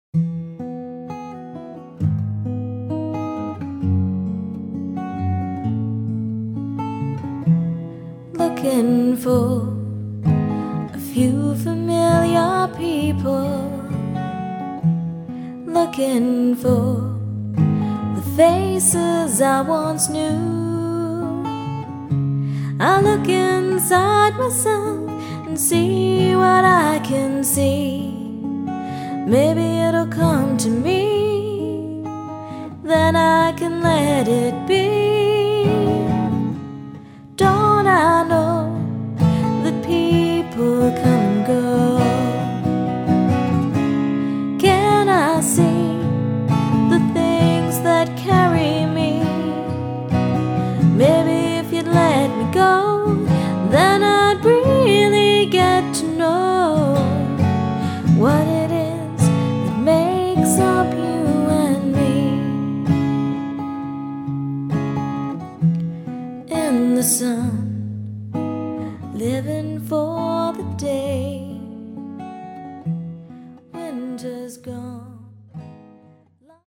guitar-vocal duo
guitar solo